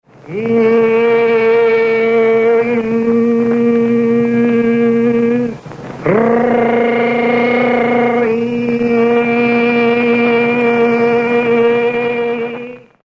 Se você quiser ouvir o Mantra INRI vocalizado